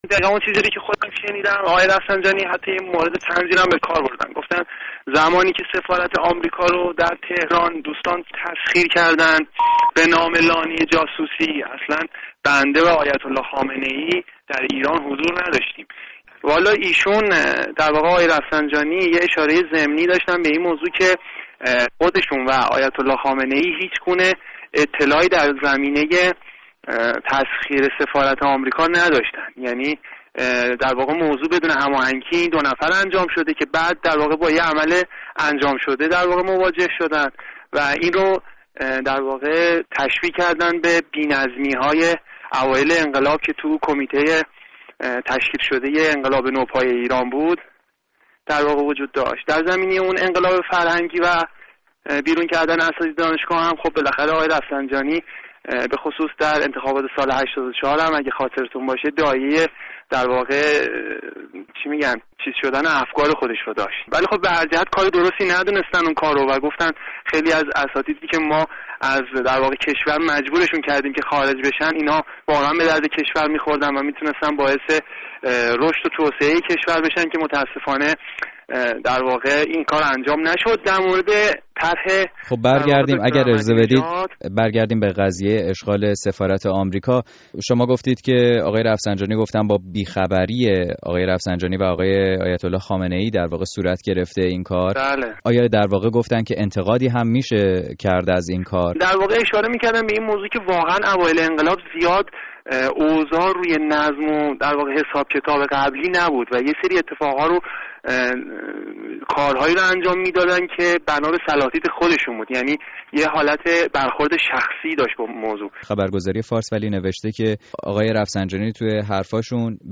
گفتگوی رادیو فردا با یک دانشجوی حاضر در سخنرانی آقای رفسنجانی